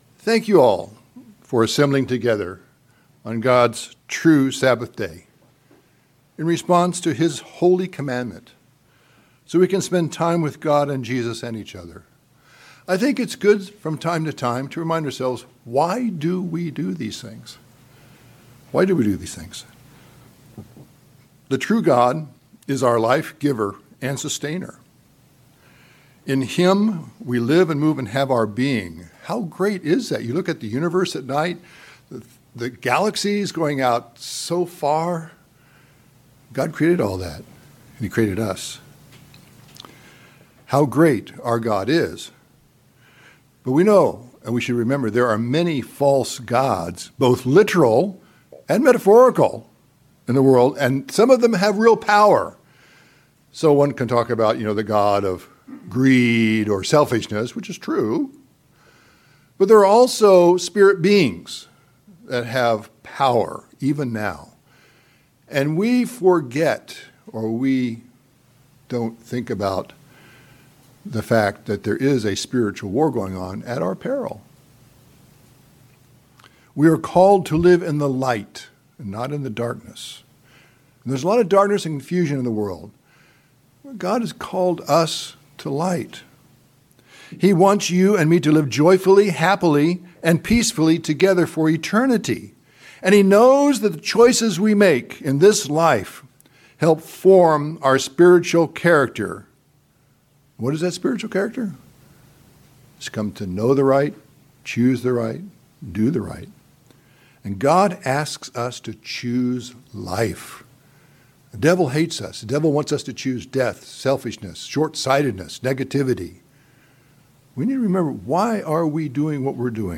Bible study type narrative discussion of two main themes of Acts 10-28.
Given in Northern Virginia